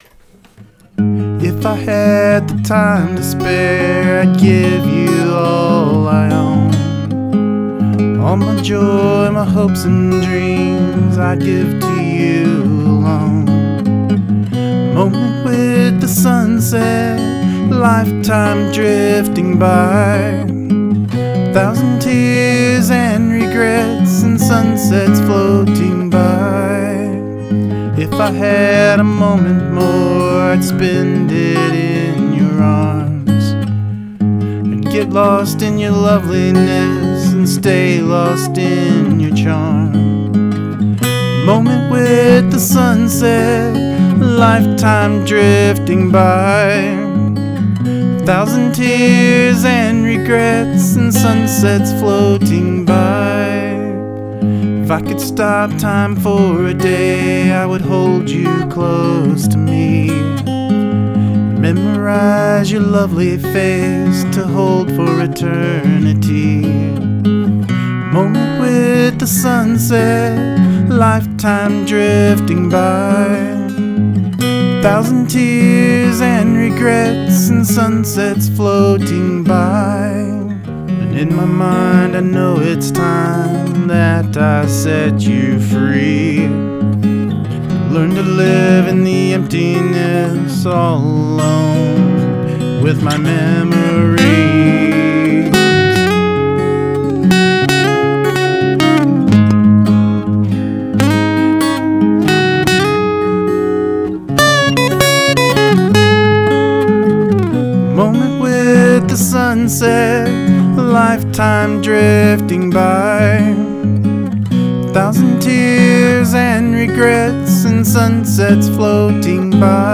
Recording suffered as a result, but it isn't all lost.
Excellent finger picking!!
An enjoyable listen, love the vibrato too!
Oh, yeah - Nice chords, very nice!
Beautiful melody and sweet romantic lyrics....